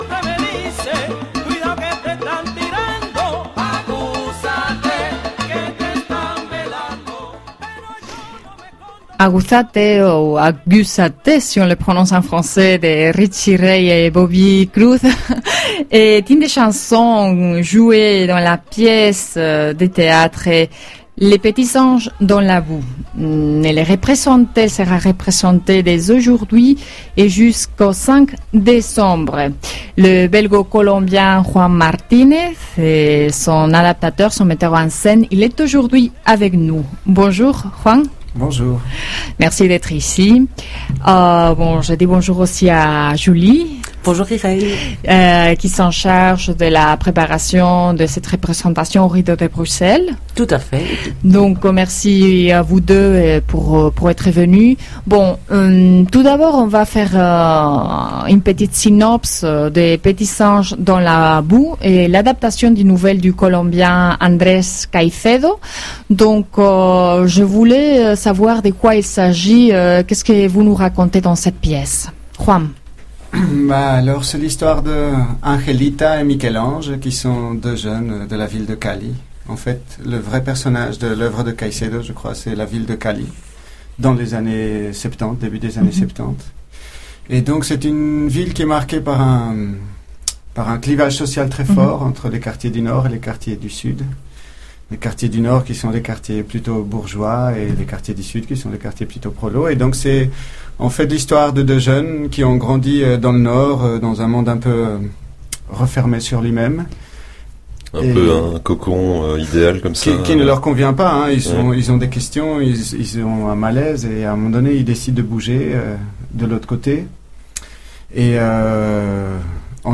Version française : Emission Radio Alma 17.11.15